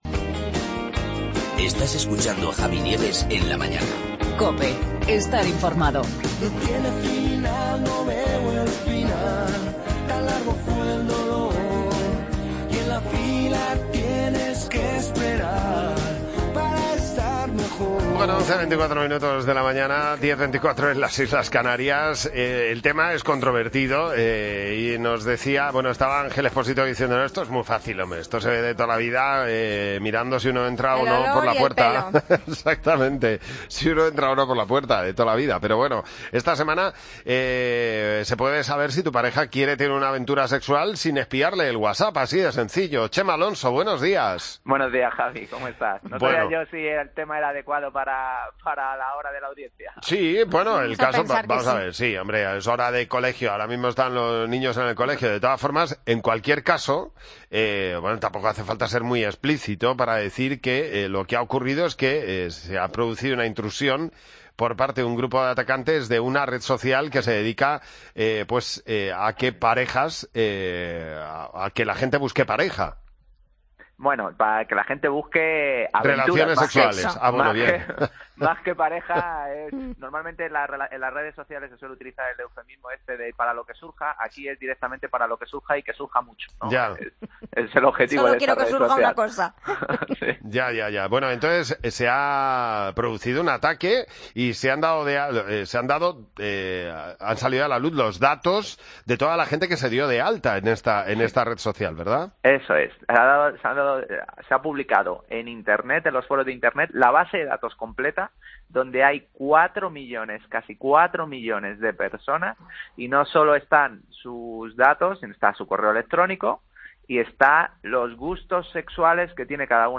AUDIO: Te lo cuenta el experto en seguridad en redes, Chema Alonso